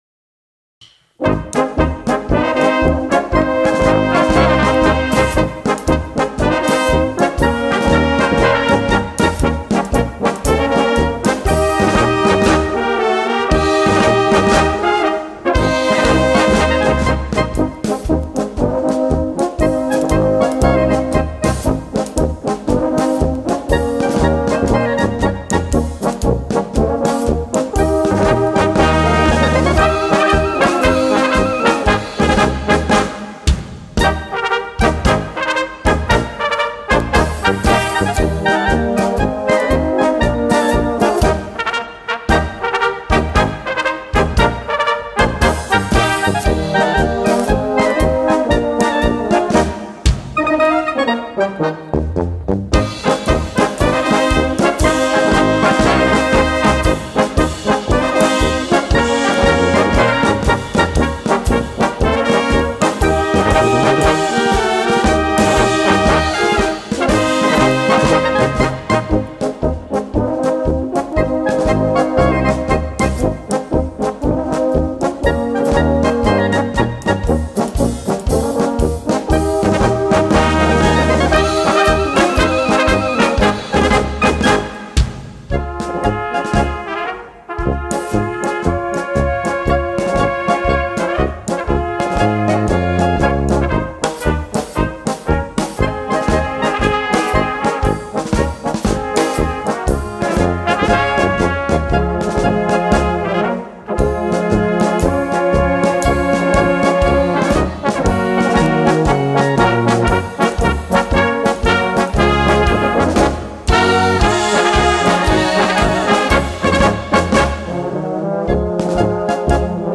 Gattung: Polka für Blasorchester
Besetzung: Blasorchester